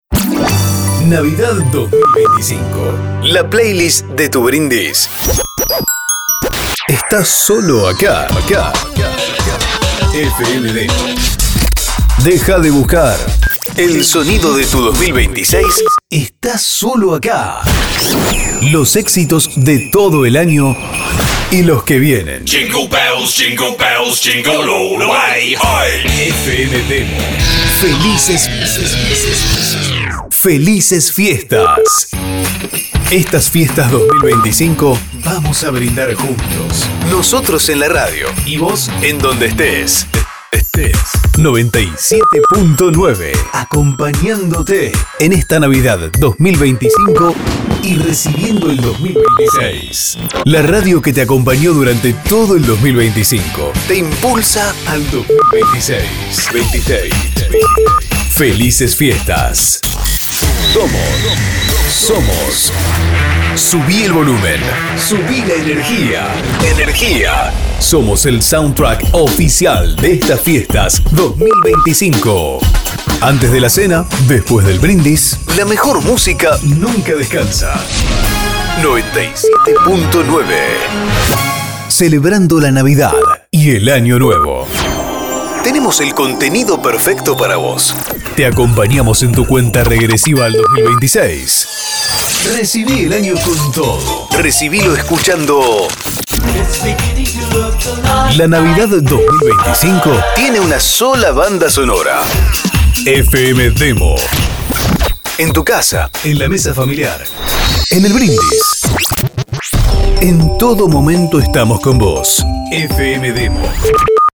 Artística de Navidad y Año nuevo premium personalizada con el nombre de tu radio, frecuencia, slogan. La solución perfecta para vestir la emisora en esta época del año.